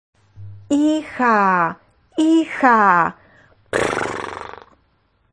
7⃣ Wyrazy dźwiękonaśladowcze – Onomatopeje
• Edunutka odtwarza dźwięk odpowiadający obrazkowi, np. dla auta – „brum brum”.